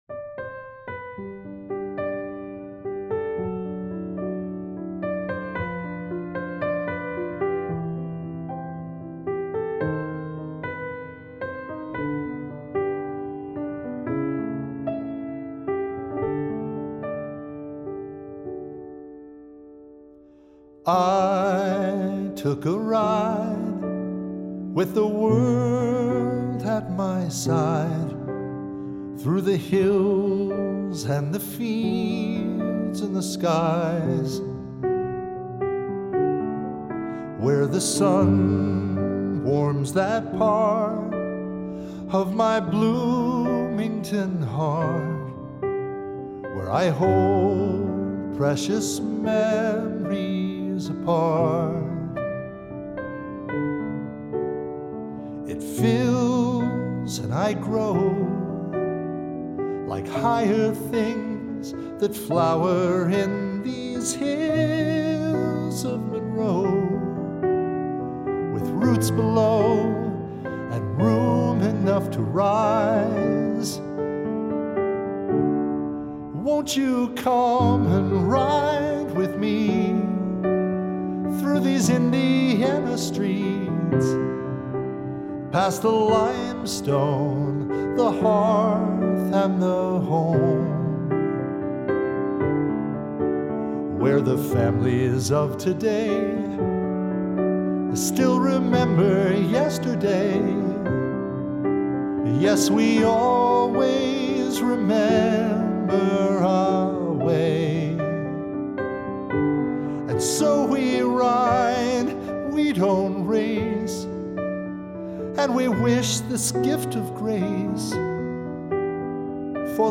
Piano/Voice Sheet Music -